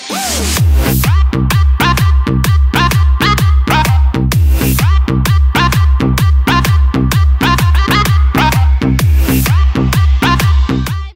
Música electro swing